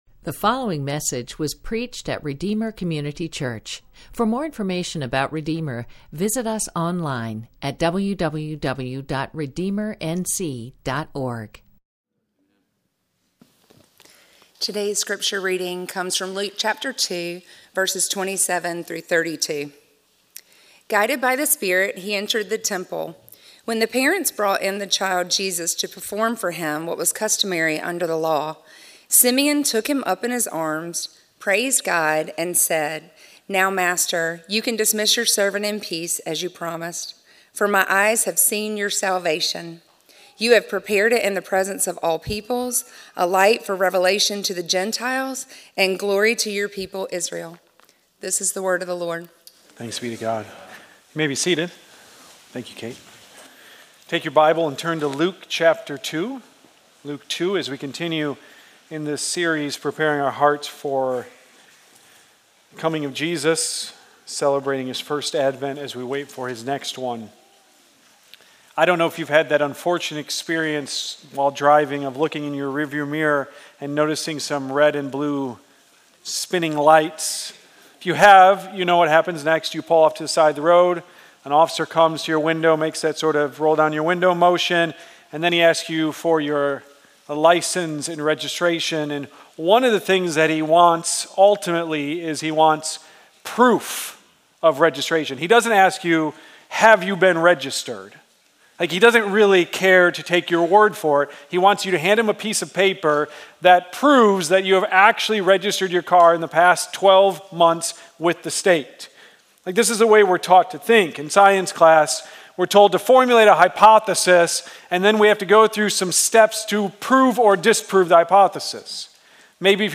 A sermon from the series "Son of the Most High."